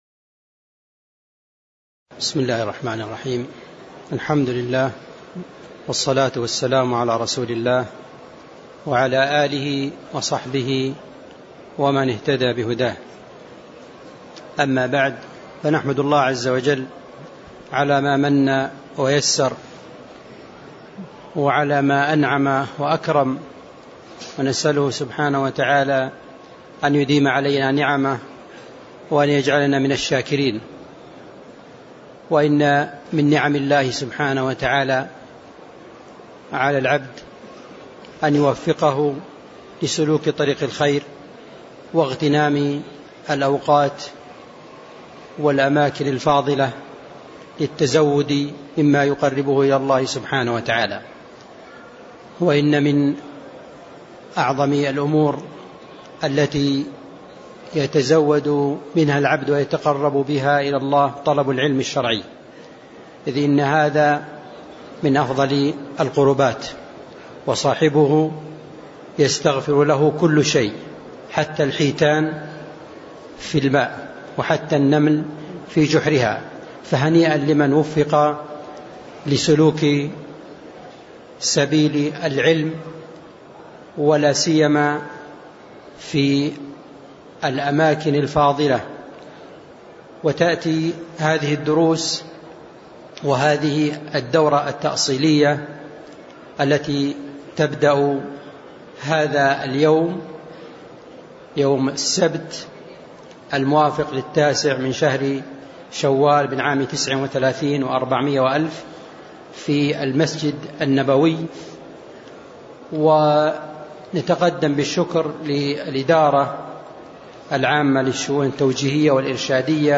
تاريخ النشر ٩ شوال ١٤٣٩ هـ المكان: المسجد النبوي الشيخ